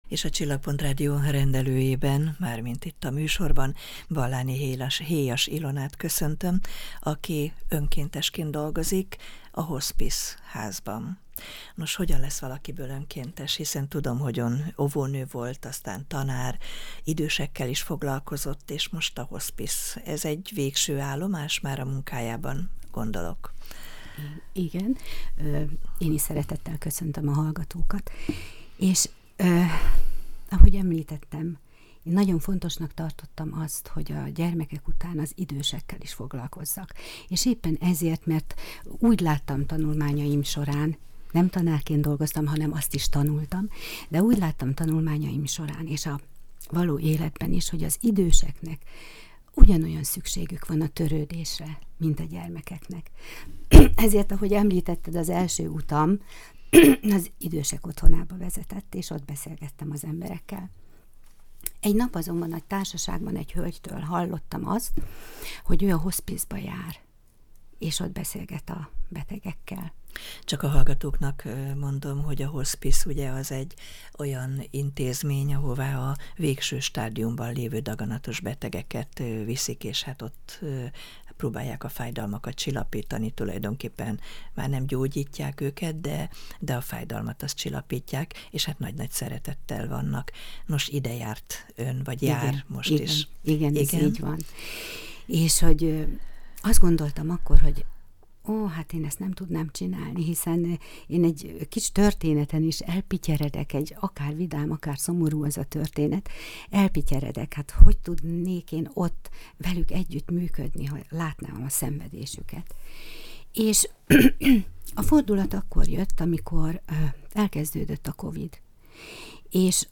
Hogy miért csinálja, erről vall, az interjúban.